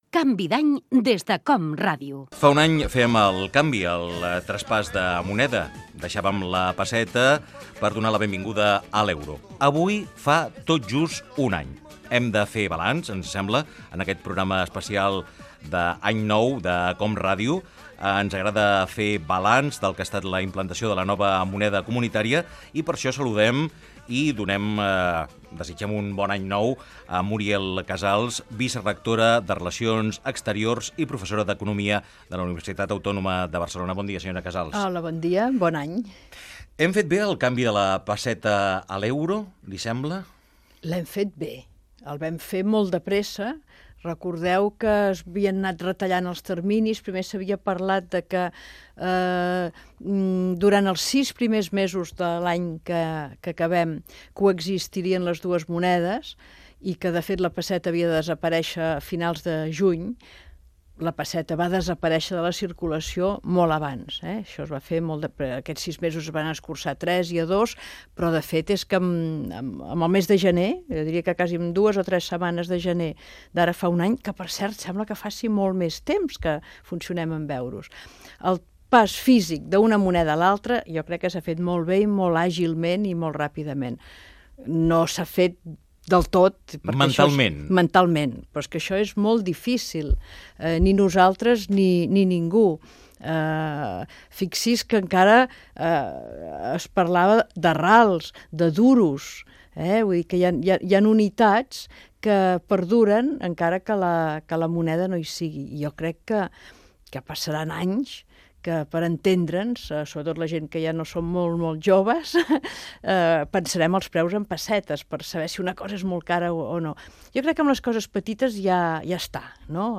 Entreteniment
FM
Fragment extret de l'arxiu sonor de COM Ràdio